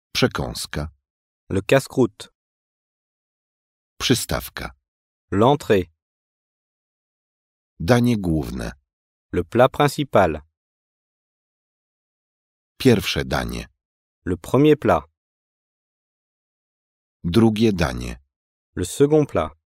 - 212 minut wysokiej jakości nagrań - profesjonalni lektorzy,
Wszystkie słówka, zwroty oraz dialogi zostały udźwiękowione przez profesjonalnych lektorów (rodowitych Francuzów).
Fragment nagrania - słówka